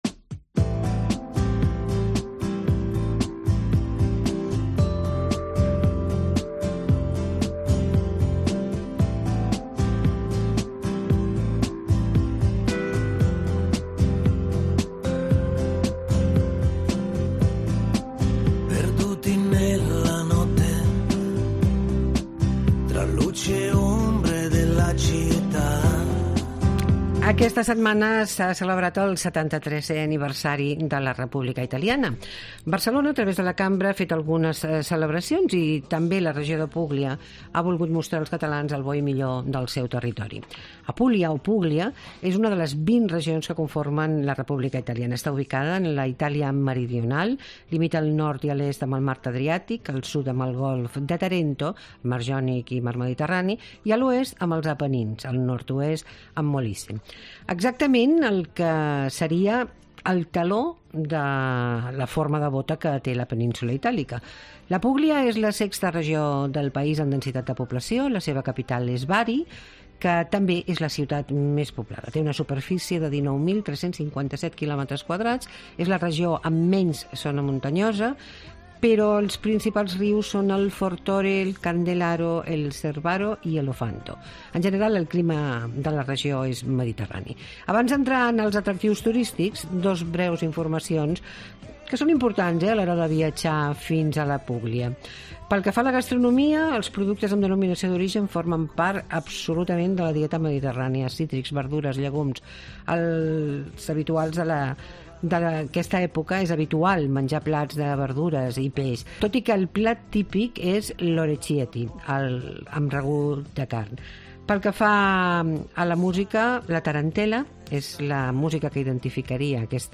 Todos los fines de semana hacemos tres horas de radio pensadas para aquellos que les gusta pasarlo bien en su tiempo de ocio ¿donde?